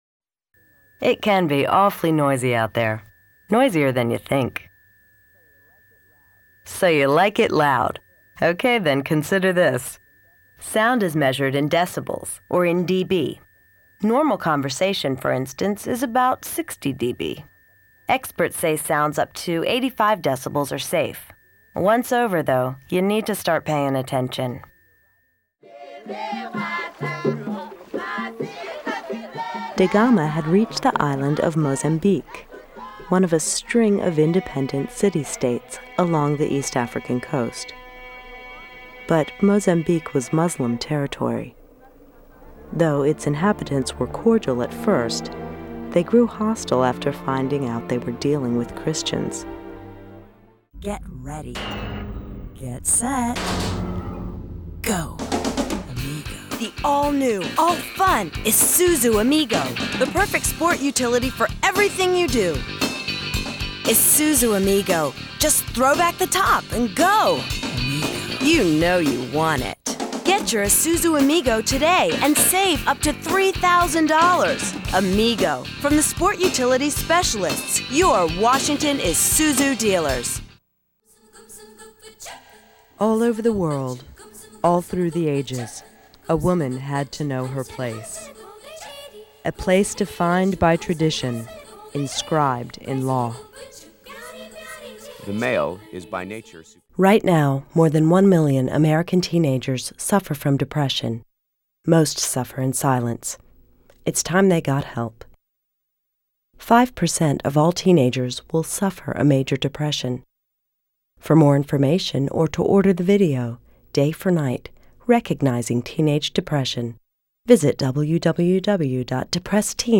VOICE OVER REEL